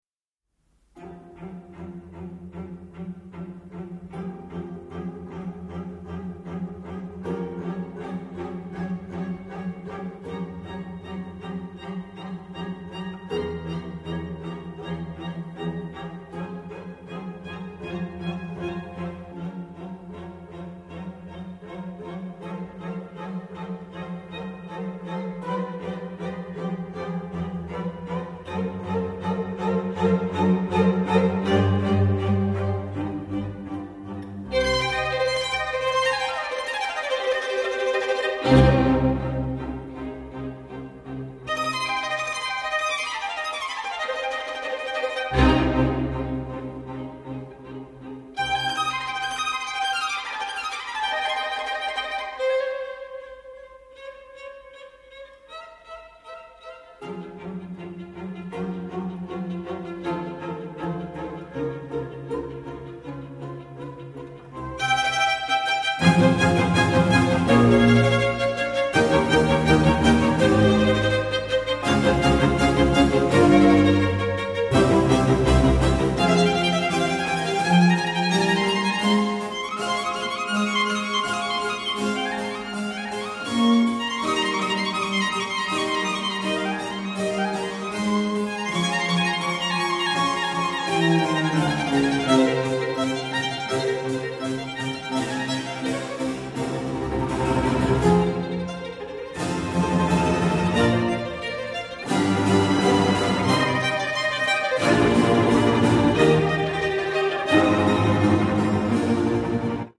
★ 多位國際級大師演繹經典名曲，以各自音樂造詣展現小提琴最千變萬化、無窮無盡的魅力！
Allegro non molto 3:07